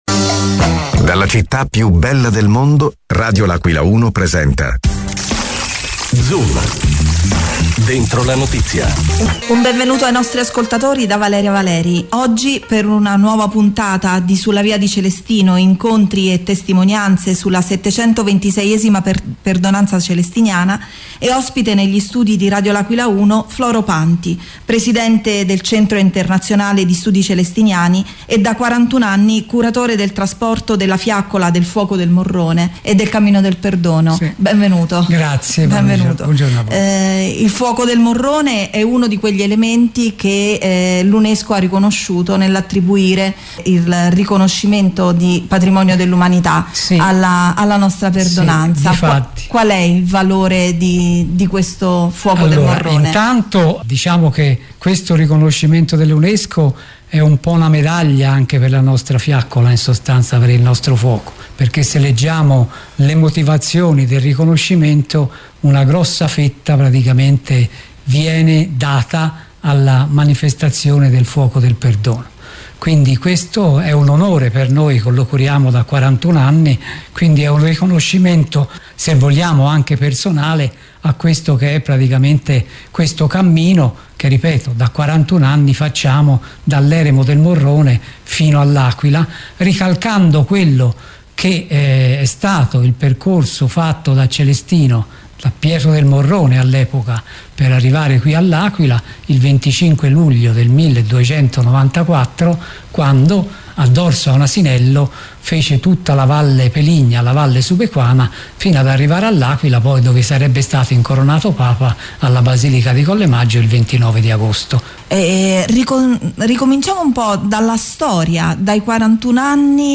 ospite negli studi di Radio L’Aquila 1